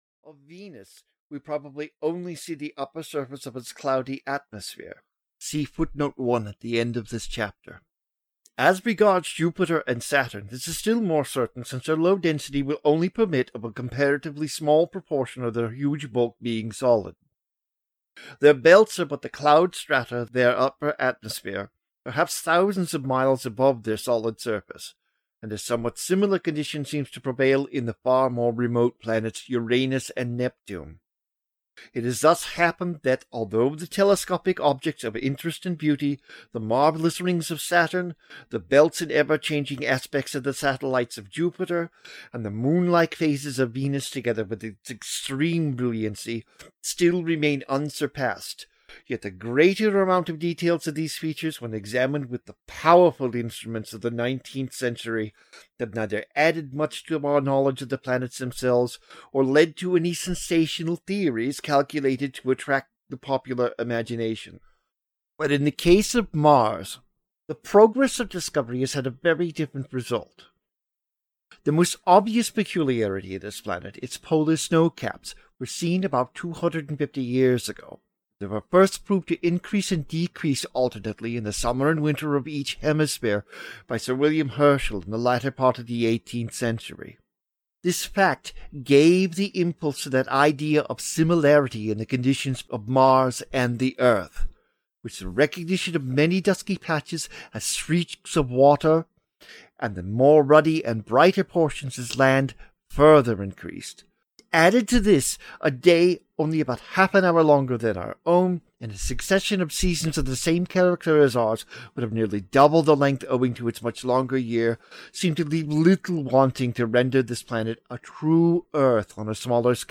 Audio knihaIs Mars Habitable? (EN)
Ukázka z knihy